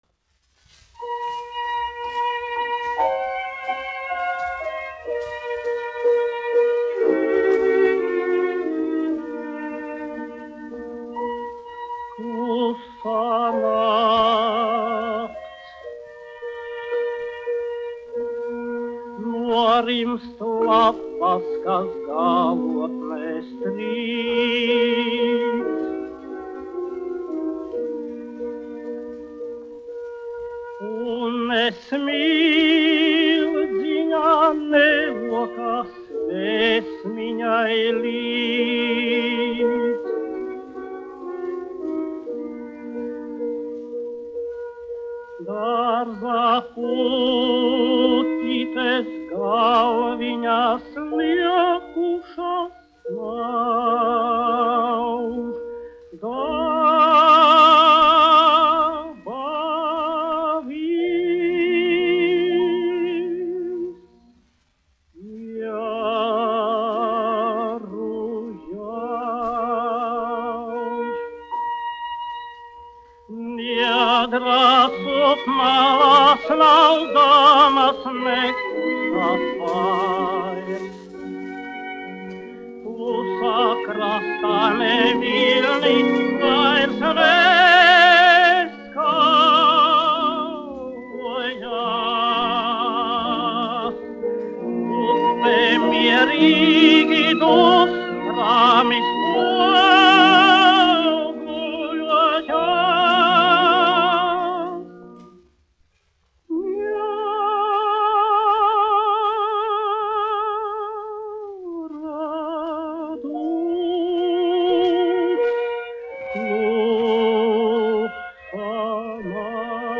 1 skpl. : analogs, 78 apgr/min, mono ; 25 cm
Romances (mūzika)